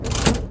lever.ogg